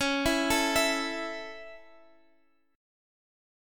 A5/C# chord